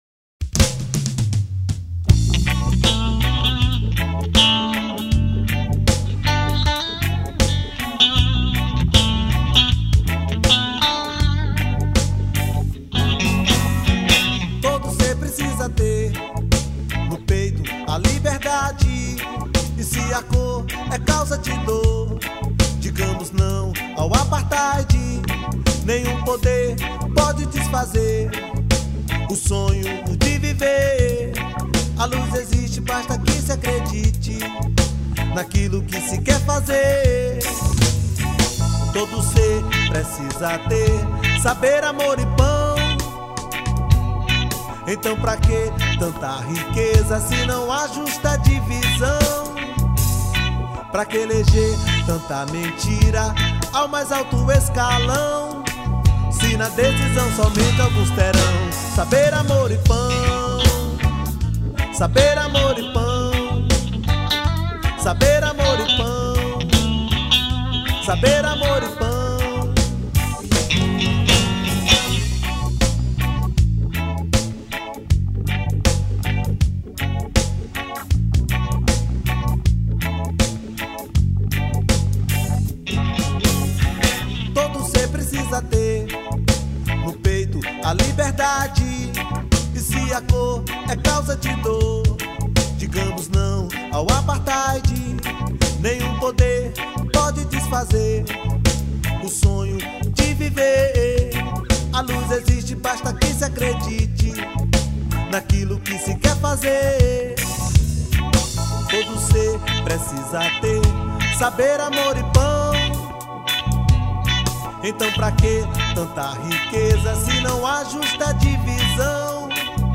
2613   02:53:00   Faixa: 5    Reggae